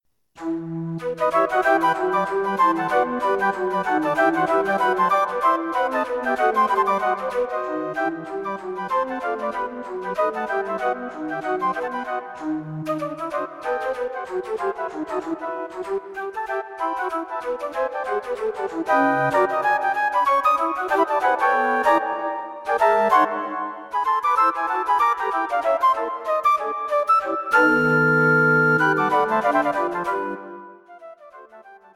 This modern Jazz piece has a hint of the exotic about it.